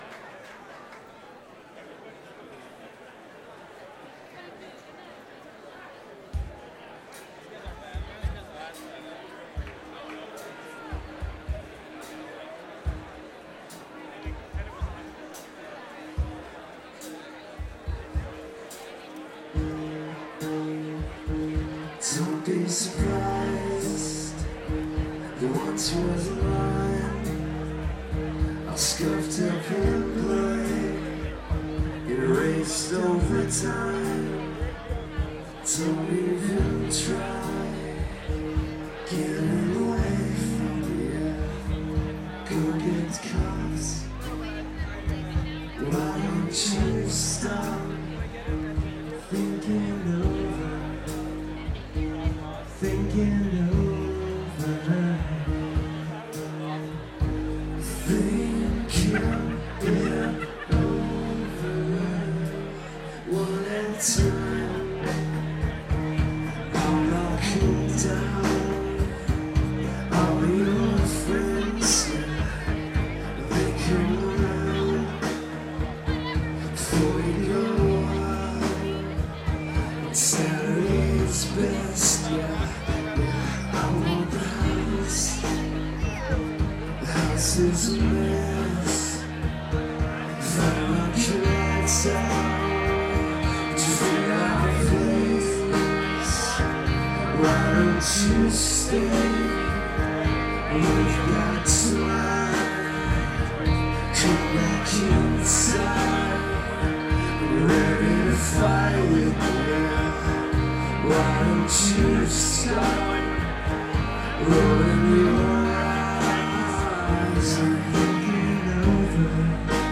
new york city march 3 2003